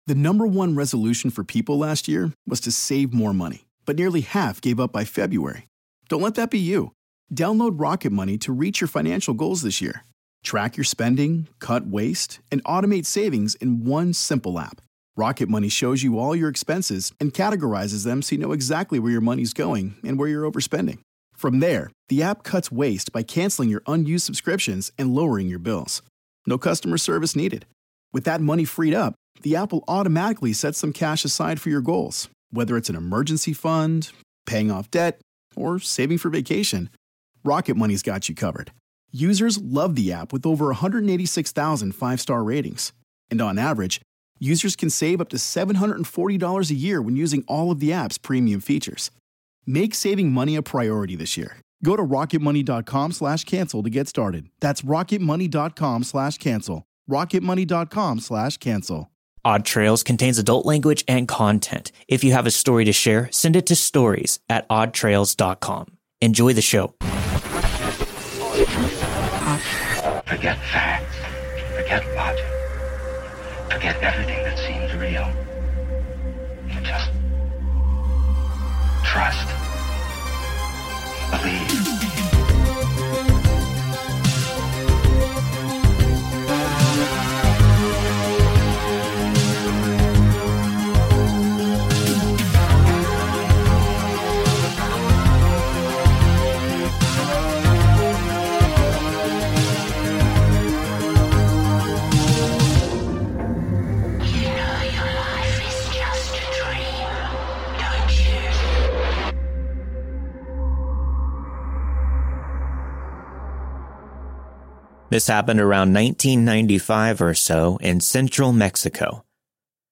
All stories were narrated and produced with the permission of their respective authors.